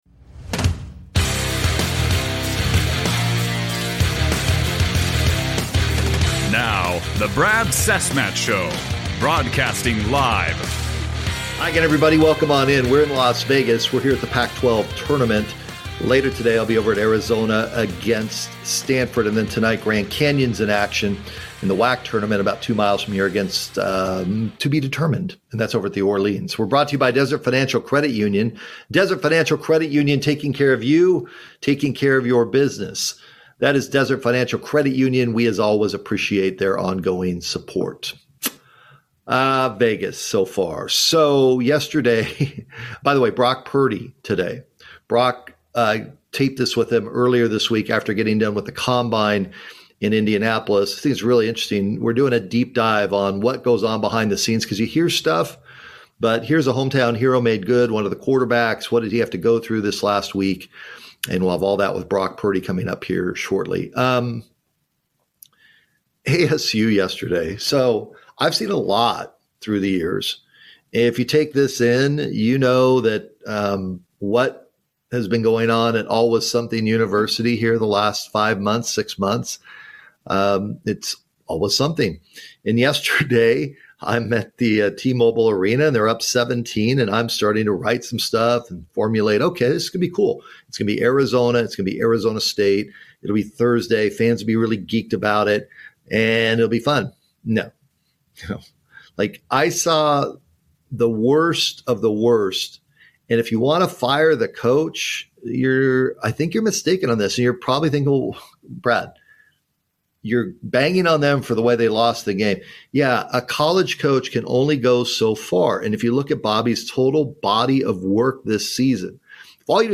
Live From Las Vegas, Brock Purdy Combine Talk